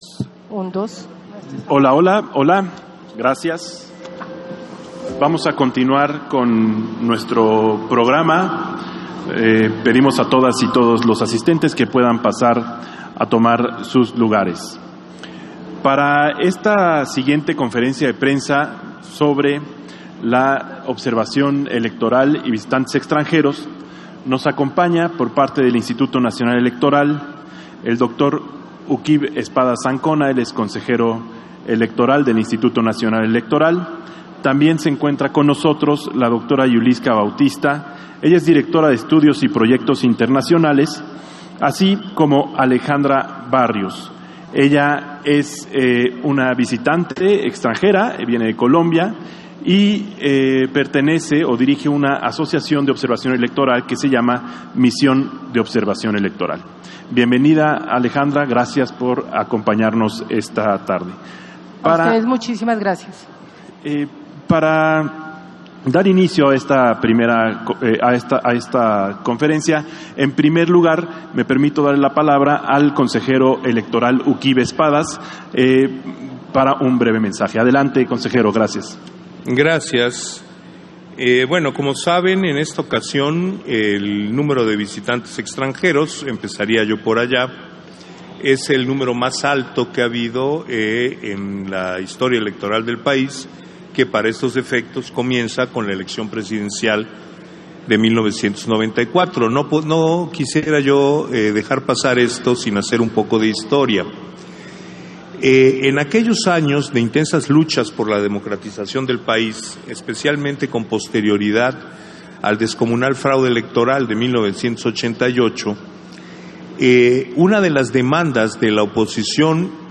310524_AUDIO_CONFERENCIA-OBSERVADORES-ELECTORALES-Y-VISITANTES-EXTRANJEROS